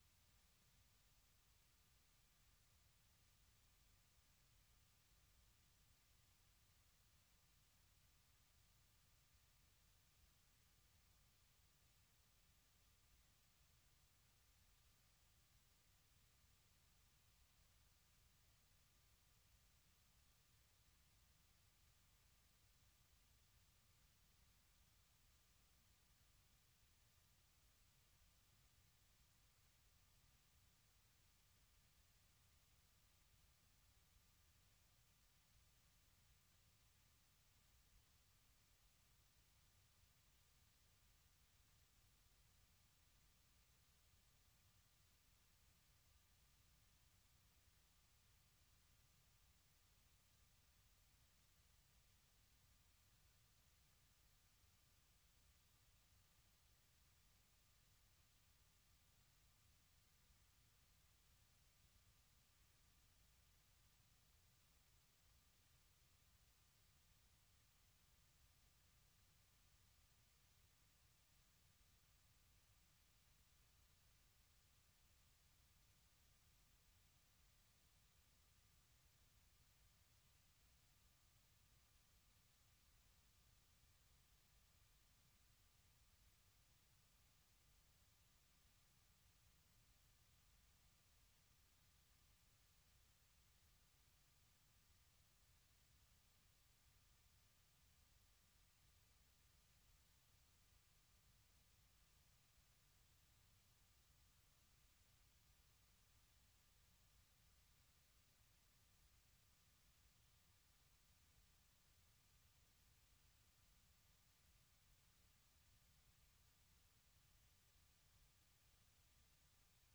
Soul USA - un retour dans les endroits mythiques de la Soul des années 60 et 70.